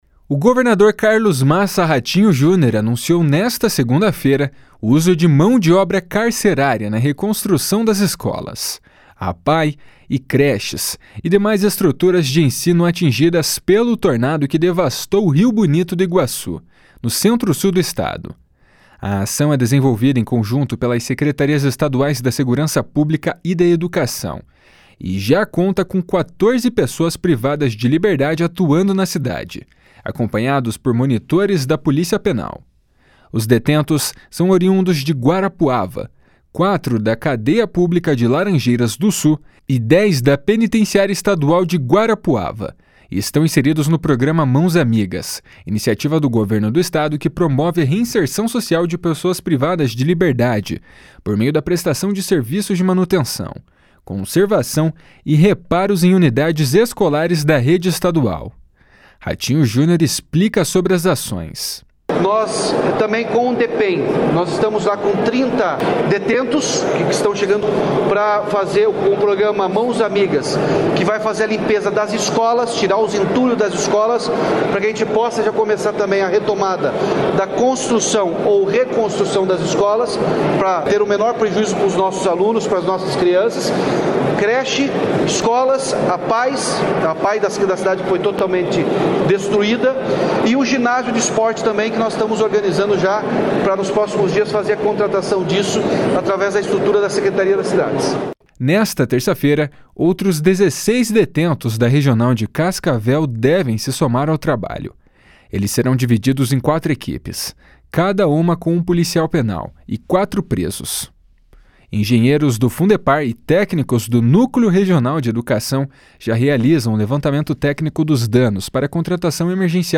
Ratinho Junior explica sobre as ações. // SONORA RATINHO JUNIOR //